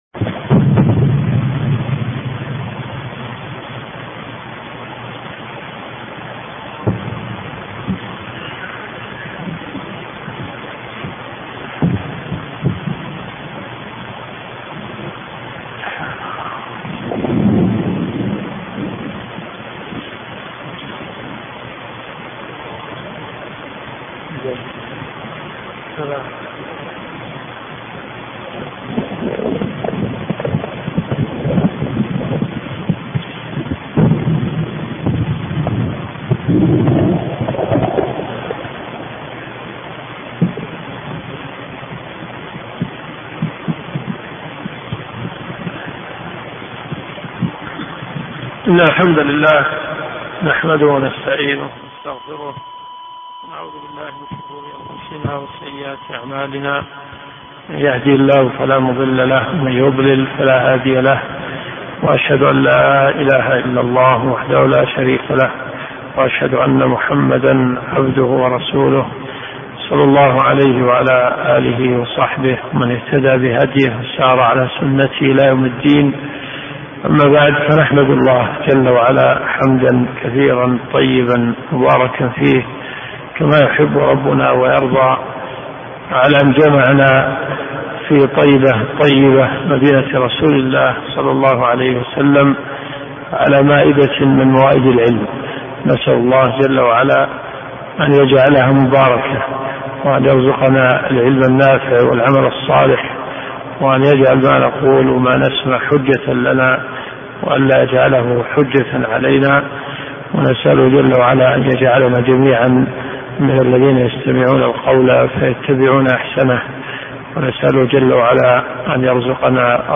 الدروس الشرعية
دروس صوتيه ومرئية تقام في جامع الحمدان بالرياض